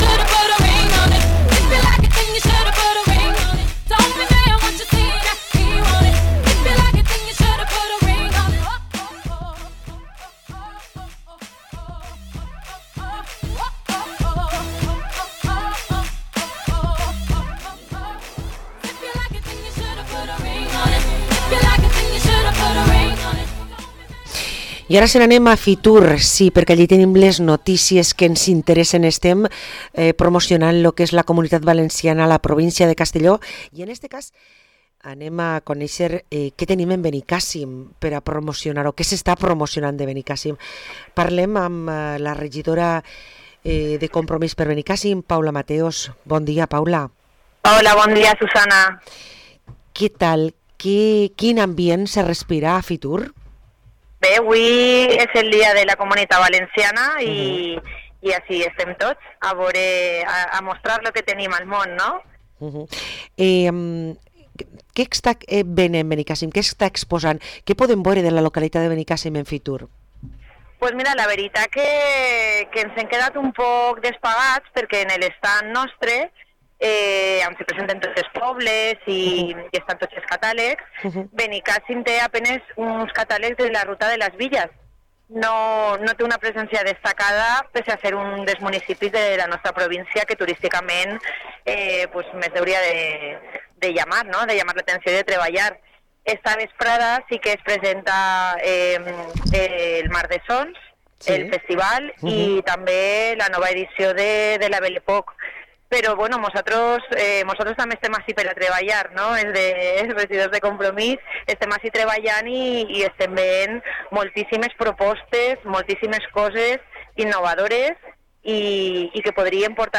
Parlem amb Paula Mateos, regidora de Compromís per Benicassim